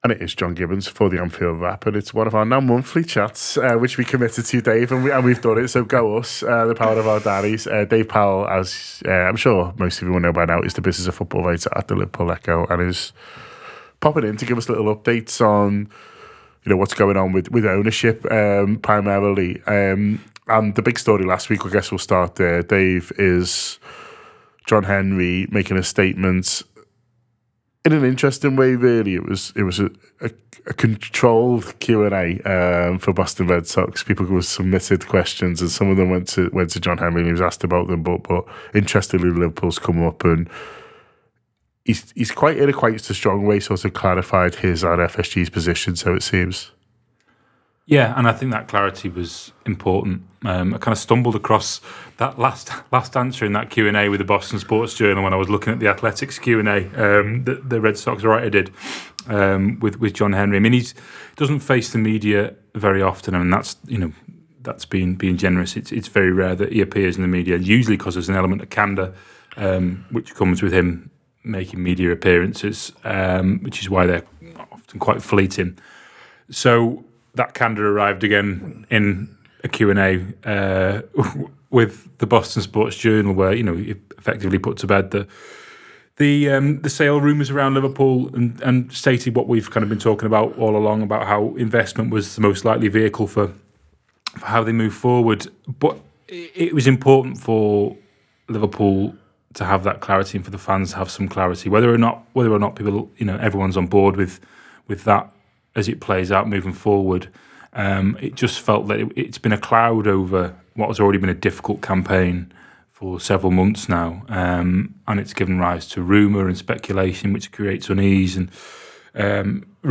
Below is a clip from the show – subscribe for more on FSG announcing Liverpool are not for sale…